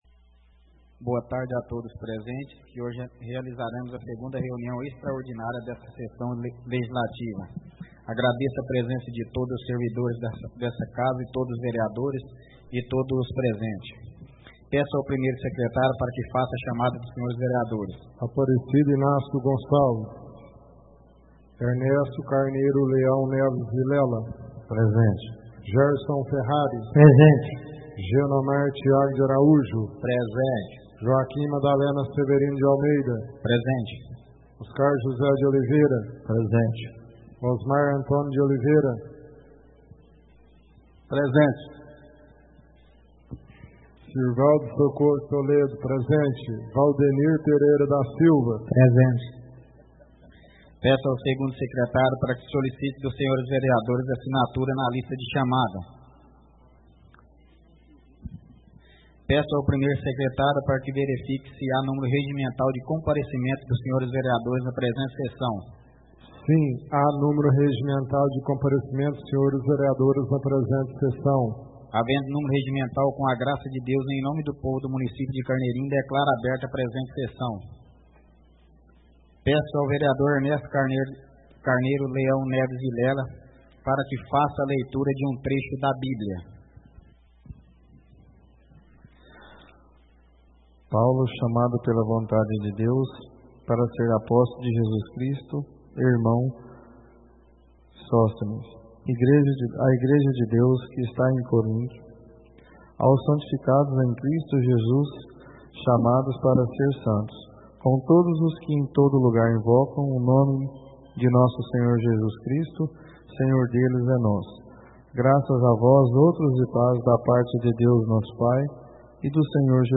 Na tarde do dia 22 (segunda-feira) de Janeiro de 2014 , aconteceu no plenário da Câmara Municipal de Carneirinho, a 02ª (segunda) reunião extraordinária do presente ano, a qual segue o resumo.
Valdenir Pereira da Silva - (PT); A sessão ocorreu dentro da normalidade, sendo transmitida na integra e para toda a comunidade pela TV e Rádio Web.